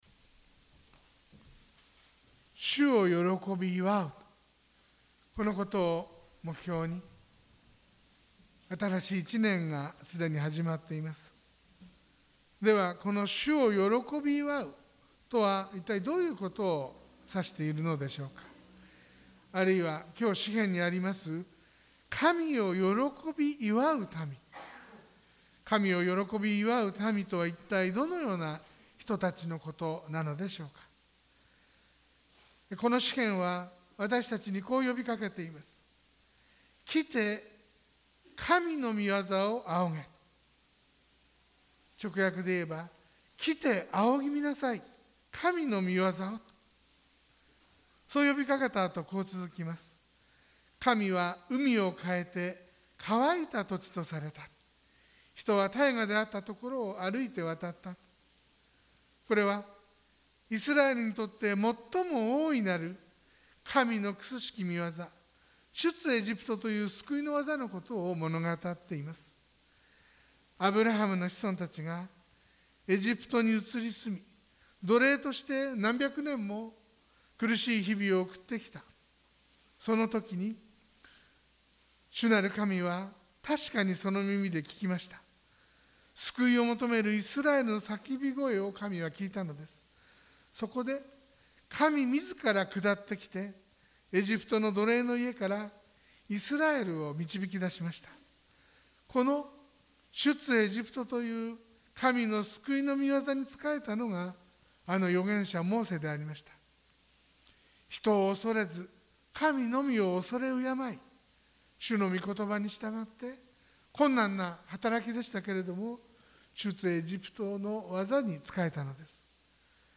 sermon-2021-01-24